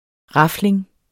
Udtale [ ˈʁɑfleŋ ]